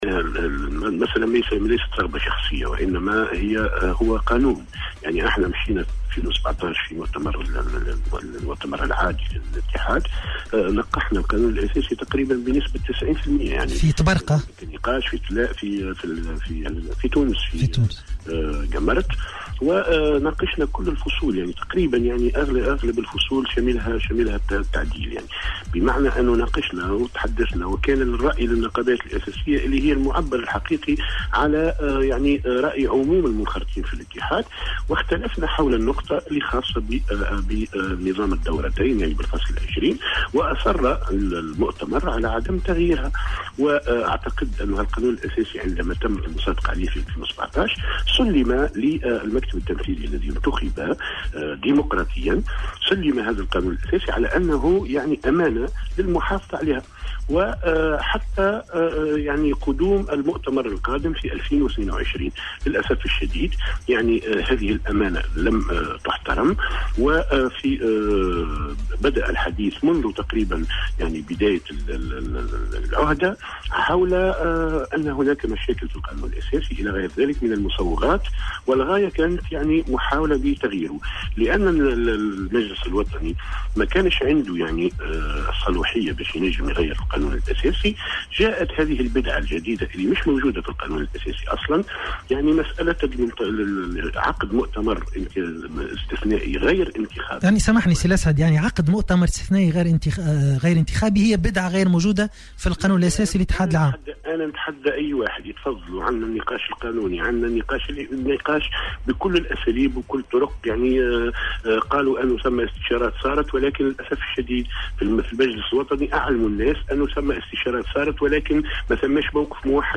وقال في مداخلة هاتفية مع "صباح الورد" على "الجوهرة أف أم" إن طريقة التصويت المكشوفة التي تمت امس اثناء انعقاد المجلس الوطني للاتحاد غير ديمقراطية ومرفوضة، كاشفا أنه رفض التصويت لاعتراضه على التحوير الذي اعتبره غير قانوني.